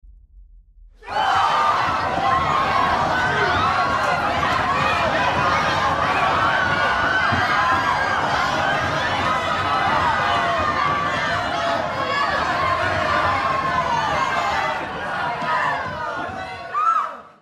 دانلود آهنگ جیغ و داد شلوغی جمعیت از افکت صوتی انسان و موجودات زنده
دانلود صدای جیغ و داد شلوغی جمعیت از ساعد نیوز با لینک مستقیم و کیفیت بالا
جلوه های صوتی